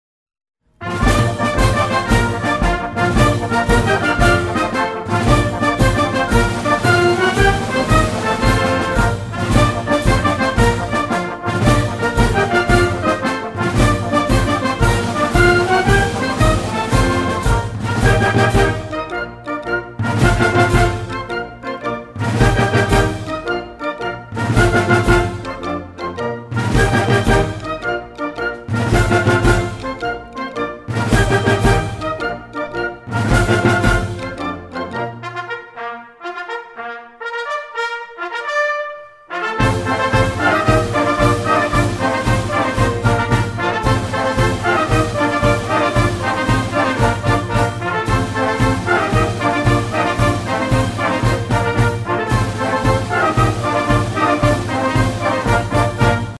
Tamborrada y Banda (Joló) y Compañías (Himno)
Desde la Plaza de San Juan hasta la Iglesia del Juncal.